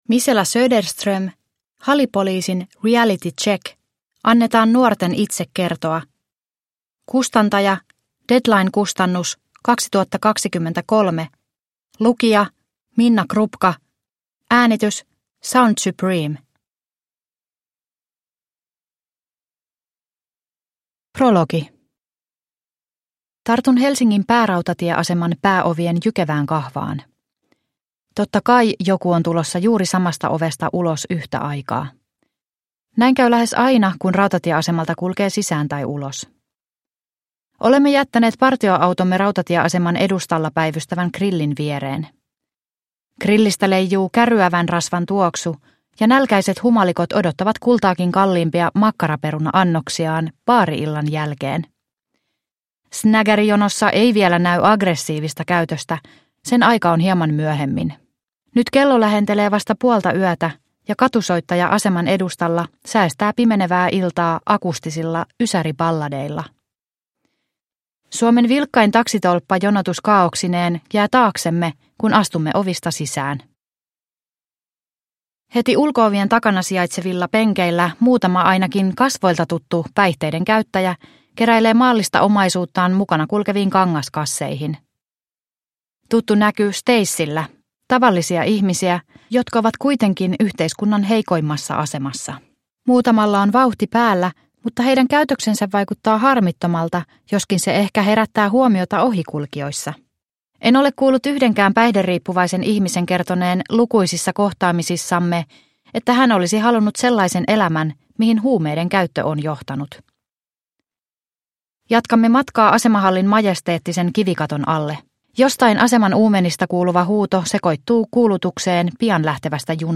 Halipoliisin reality check – Ljudbok – Laddas ner